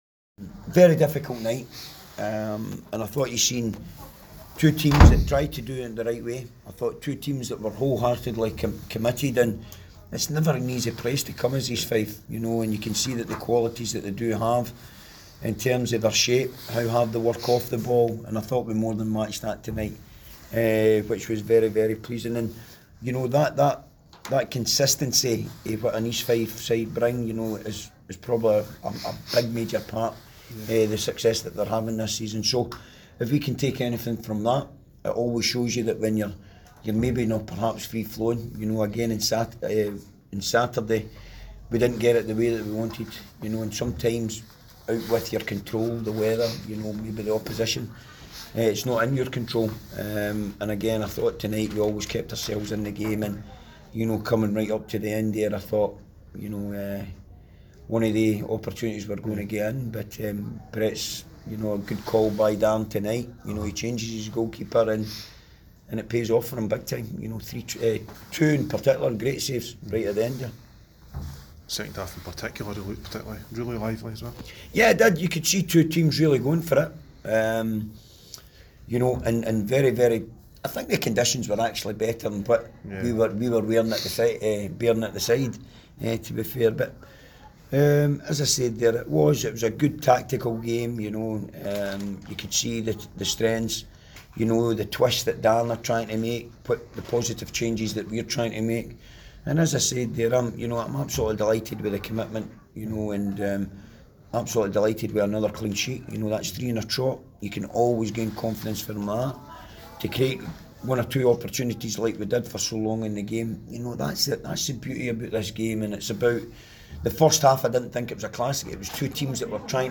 press conference after the Ladbrokes League 1 match.